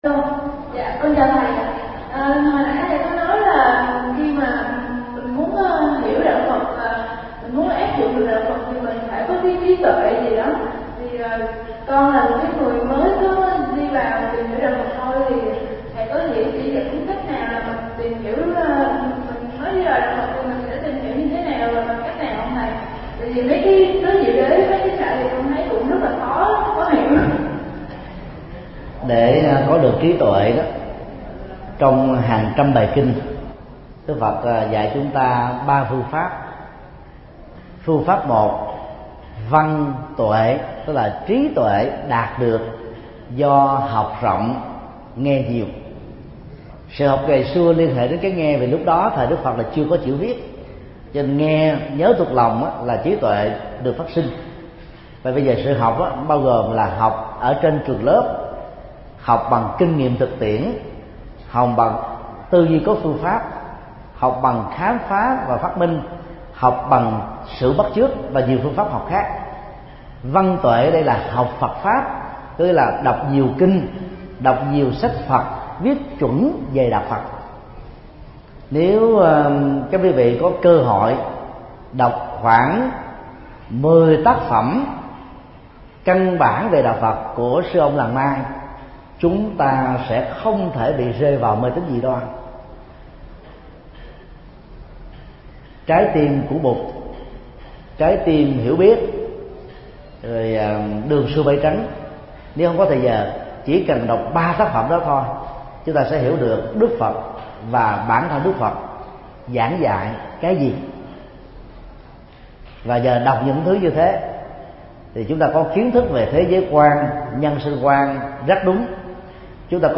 Vấn đáp: Trí tuệ trong đạo Phật